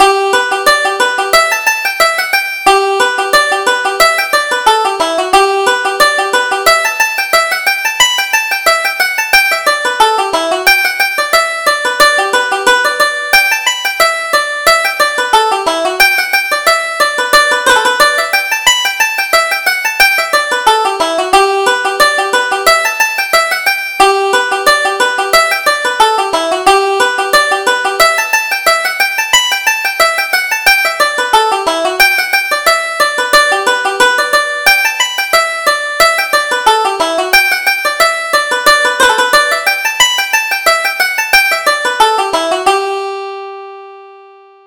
Reel: London Lasses